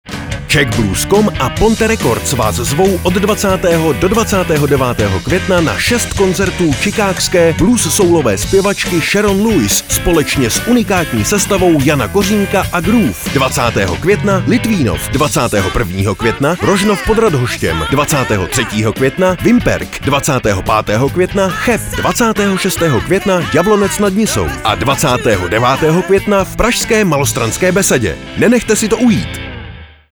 Její živá vystoupení ukazují velkou lásku k soulu a blues.
hammond organ
kytara
bicí
saxofon
trubka.